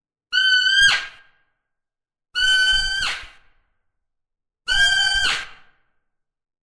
На этой странице собраны натуральные звуки косули — от нежного фырканья до тревожных криков.
Громкий звук косули